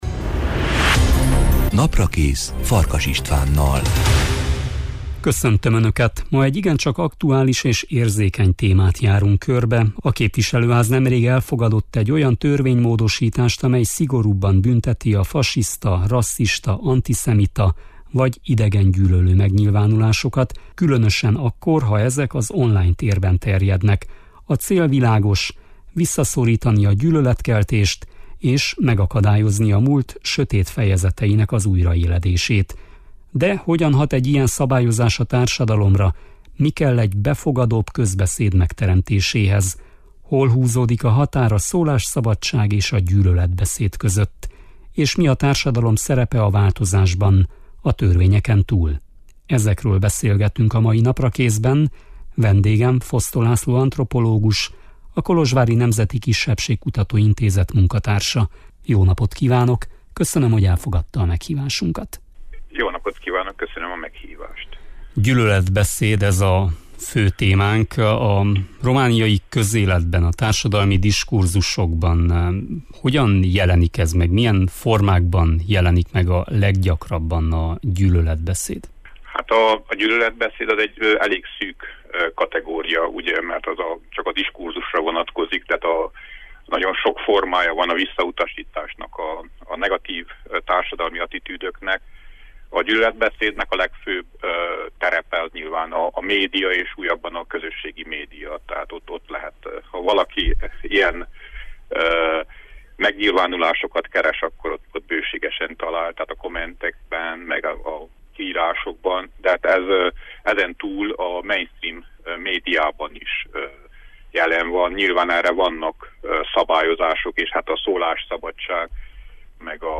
Ezekről beszélgetünk a mai Naprakészben